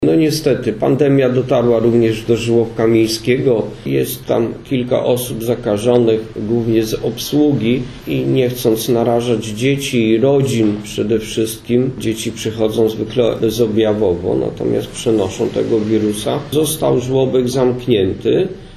Powodem zamknięcia były przypadki zakażenia wśród personelu. Mówi burmistrz Niska Walde3mar Ślusarczyk.